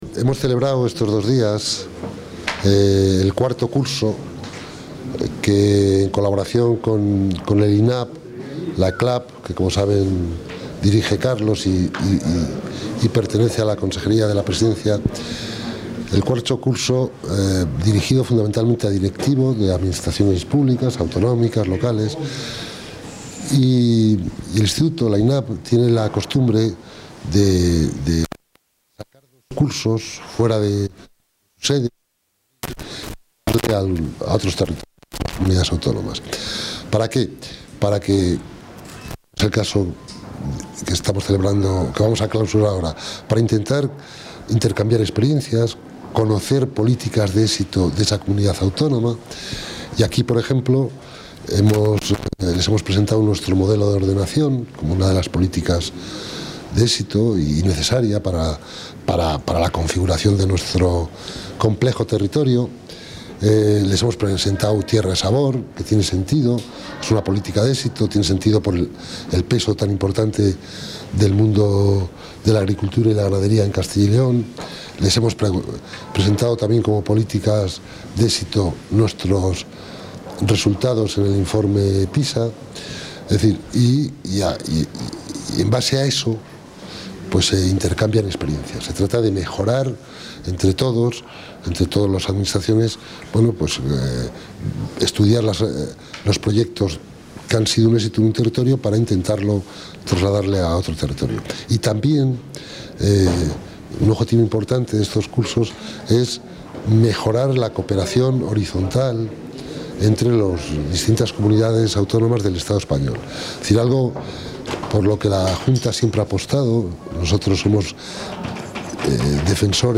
Audio intervención del vicepresidente de la Junta.
El vicepresidente y consejero de la Presidencia de la Junta de Castilla y León, José Antonio de Santiago-Juárez, ha clausurado hoy en Valladolid el IV Curso de Directivos de las Administraciones Públicas.